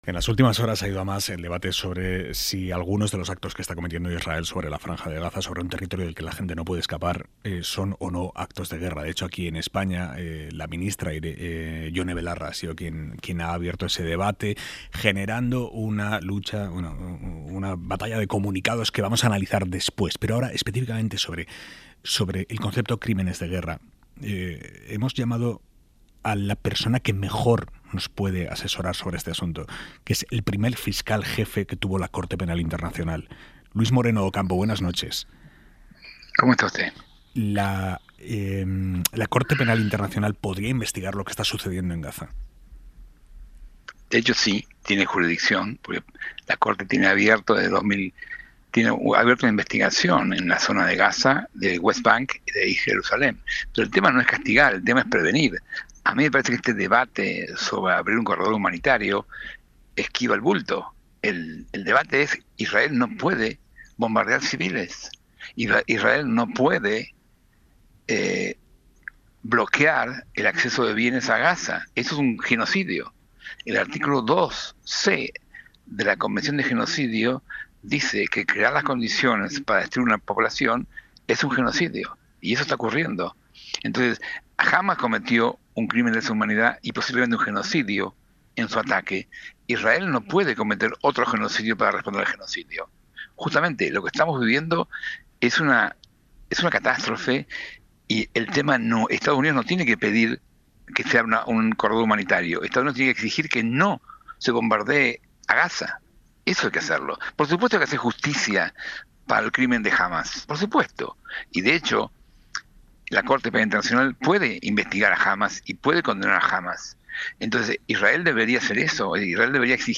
Aimar Bretos entrevista a Luis Moreno Ocampo.